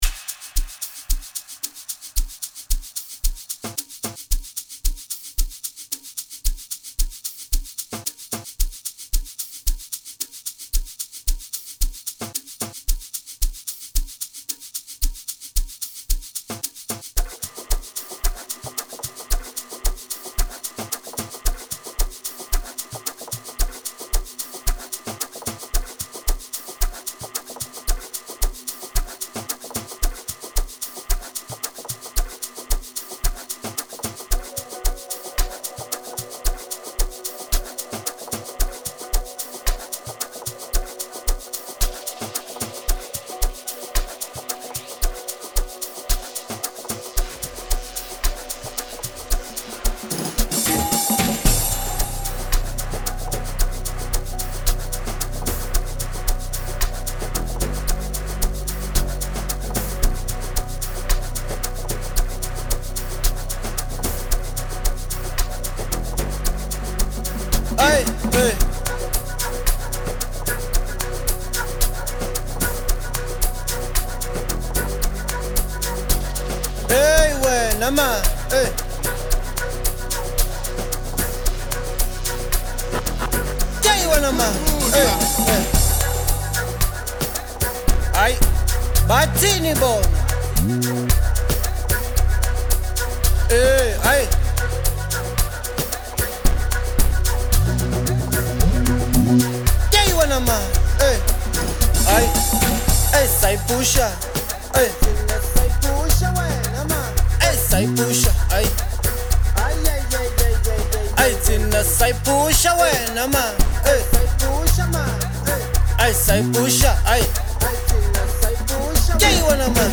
vibrant music tune
Amapiano songs